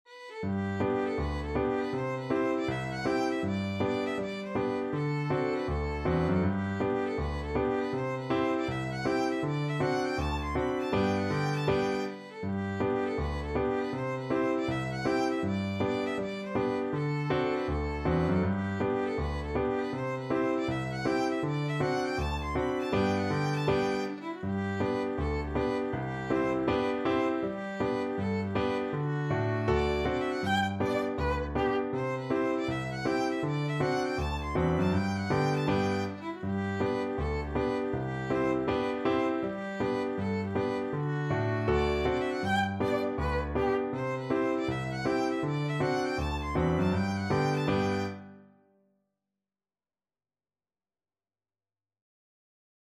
Violin
G major (Sounding Pitch) (View more G major Music for Violin )
With a Swing = c. 80
2/2 (View more 2/2 Music)
Traditional (View more Traditional Violin Music)